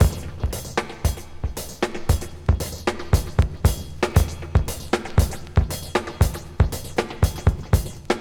• 117 Bpm High Quality Drum Loop Sample E Key.wav
Free breakbeat sample - kick tuned to the E note. Loudest frequency: 1269Hz
117-bpm-high-quality-drum-loop-sample-e-key-Fwo.wav